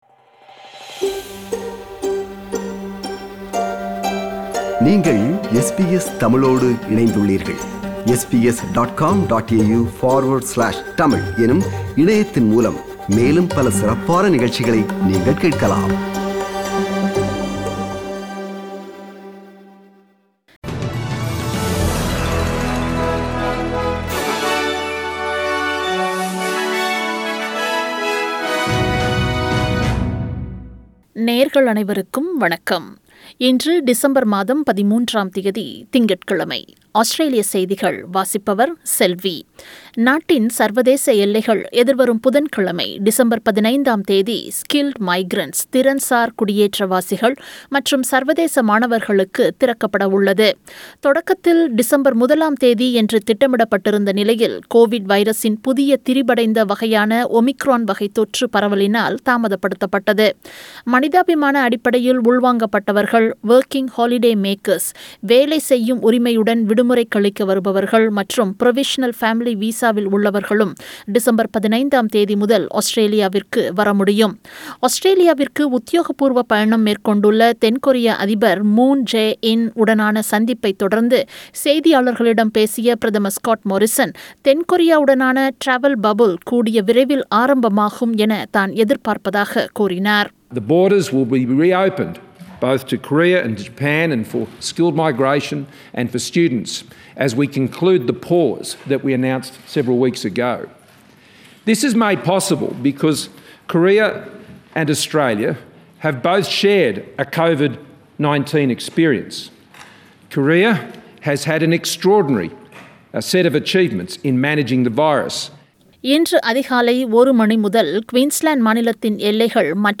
Australian News: 13 December 2021 – Monday